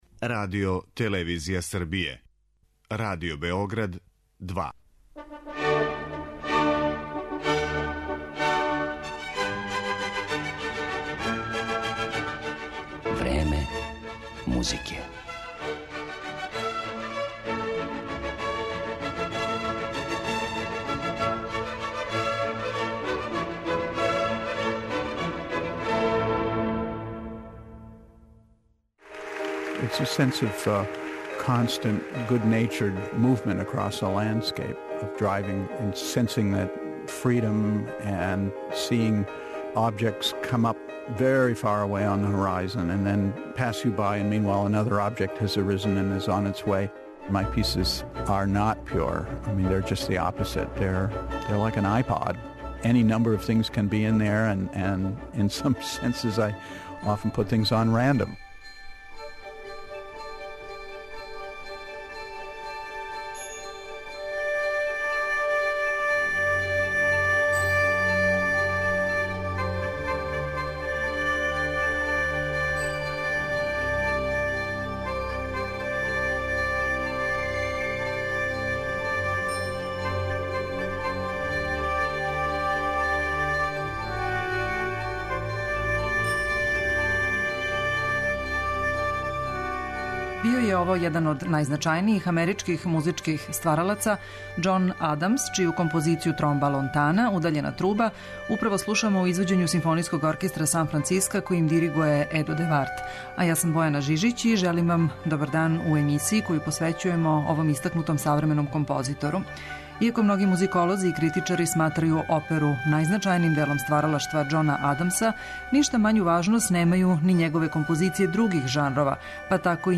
Мада многи историчари сматрају да најзначанији део стваралаштва композитора Џона Адамса јесу његове опере, овај истакнути сaвремени амерички стваралац није ништа мање инвентиван ни када пише инструменталну музику, као што ћете моћи да чујете у данашњој емисији Време музике, која му је посвећена.